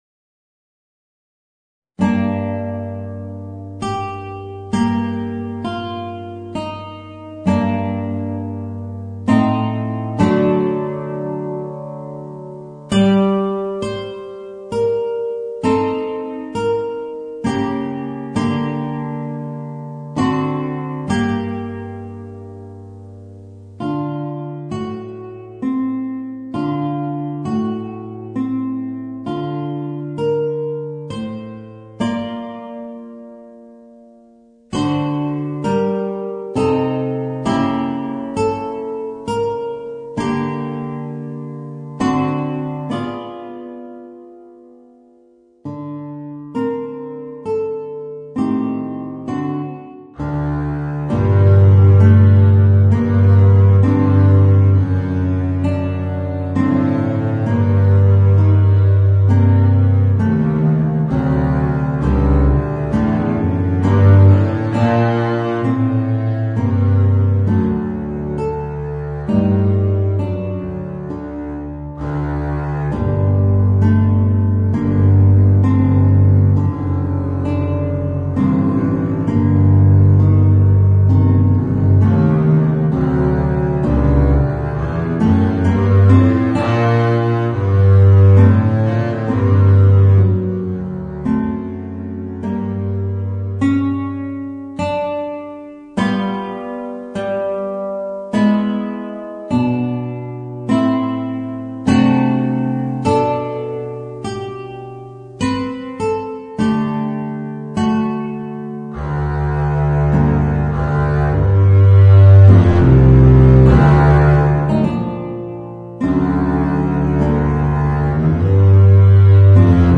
Voicing: Guitar and Contrabass